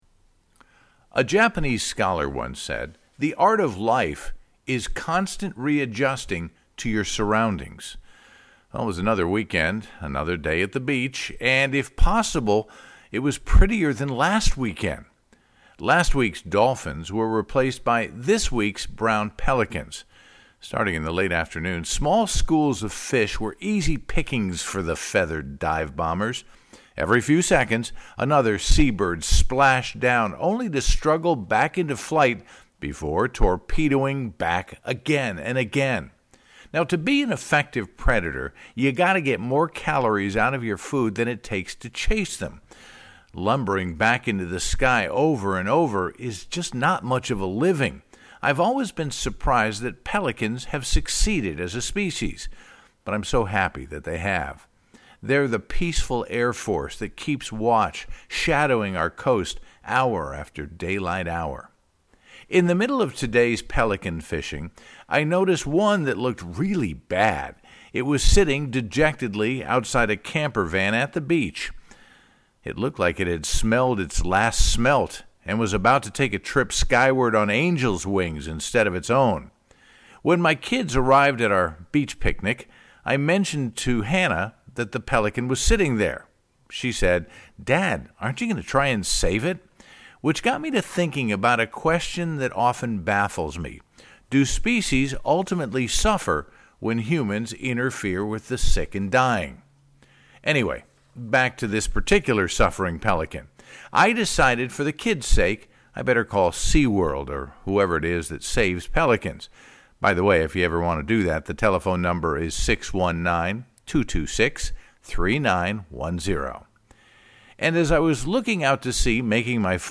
looking forward to running into you again over the next 20 or so years….chose blue for nails and toes this weekend……It seems so right…Made me smile…..love the podcast, you sound great and can hear the wheels turning in your mind….isn’t it amazing how many people you don’t even know have you in their thoughts and prayers…you are loved….